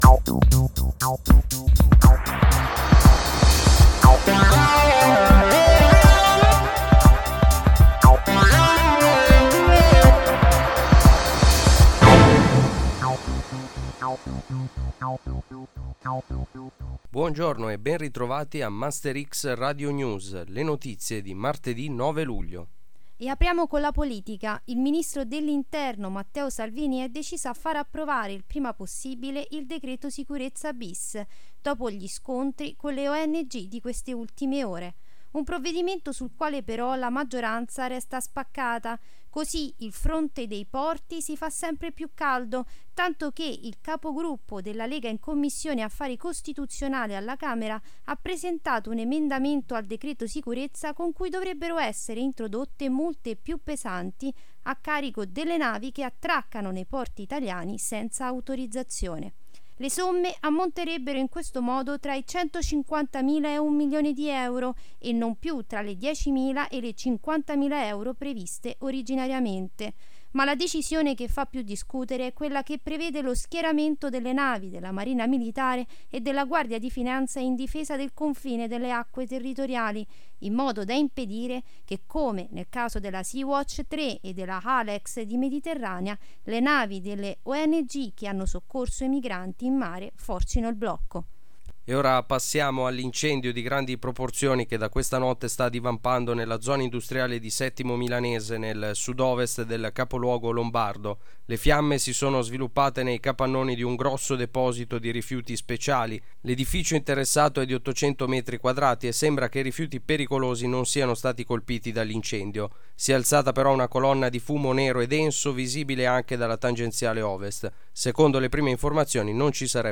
MasterX Radio News – 9 luglio